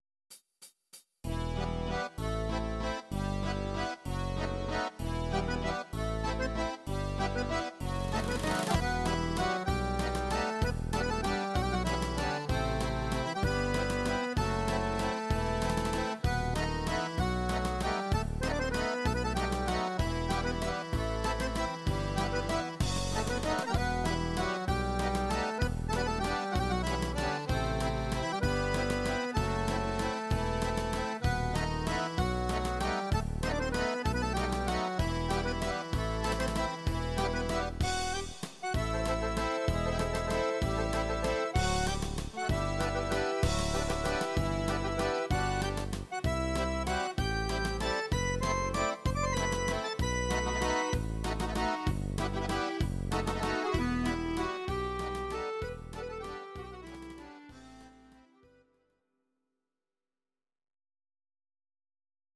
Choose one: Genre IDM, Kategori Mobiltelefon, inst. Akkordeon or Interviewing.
inst. Akkordeon